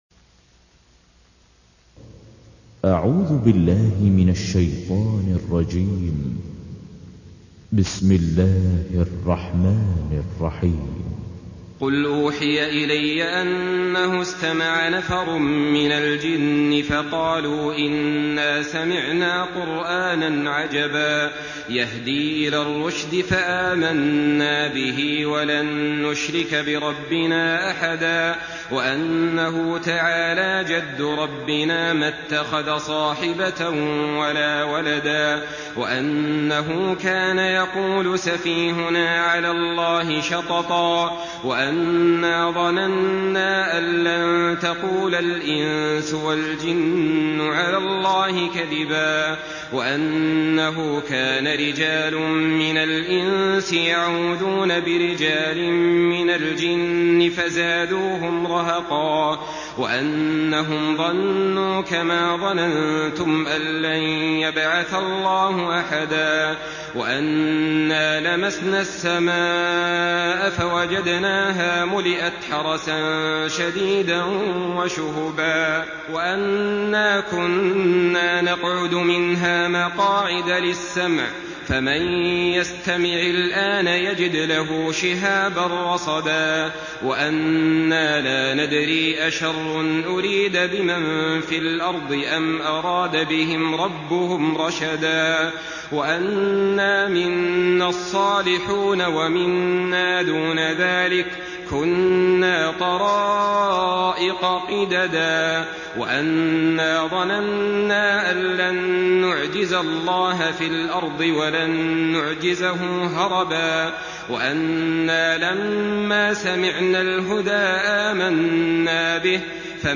Surah আল-জিন্ন MP3 by Saleh Al-Talib in Hafs An Asim narration.
Murattal